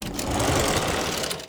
door_open.wav